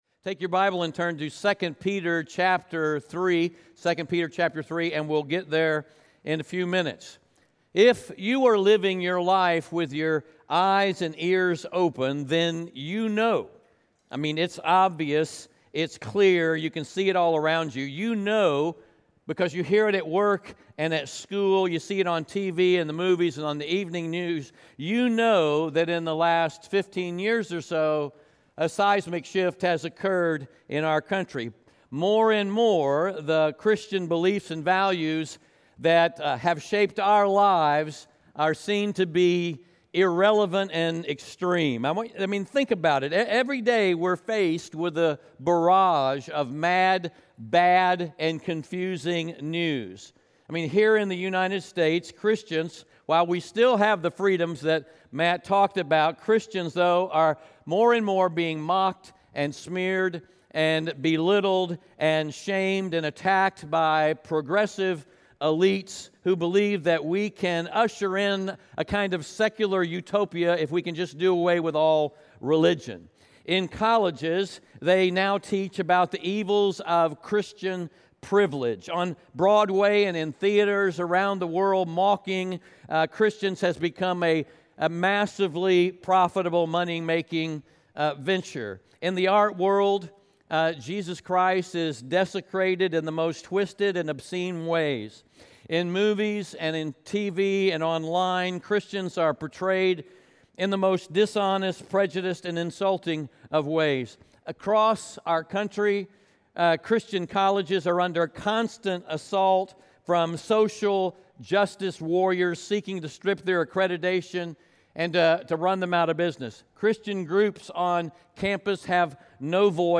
2 Peter 3:11-18 Audio Sermon Notes (PDF) Ask a Question We’ve come to the close of our series Exiles: Living in Hope based on the New Testament letters of 1 and 2 Peter.